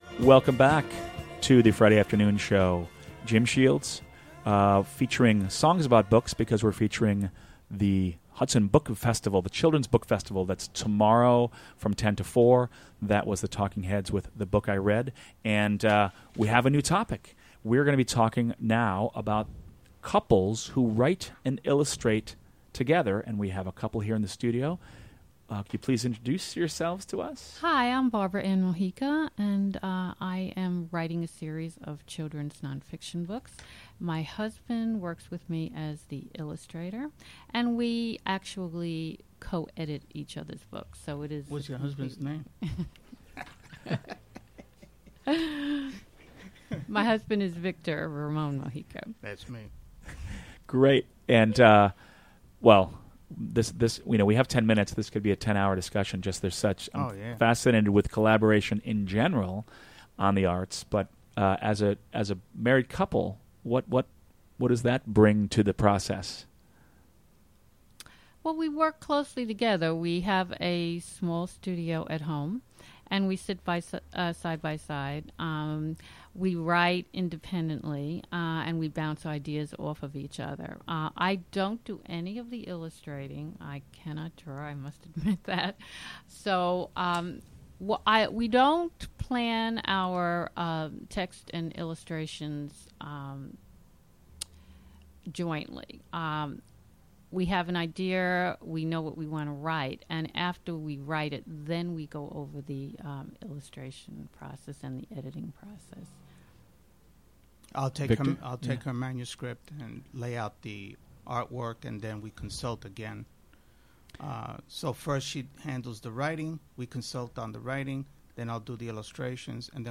Interviewed